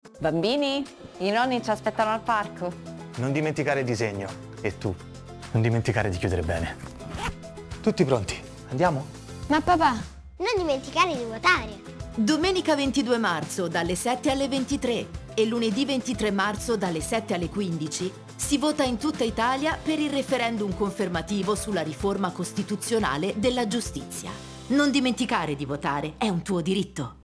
Lo spot radio
spot-referendum.mp3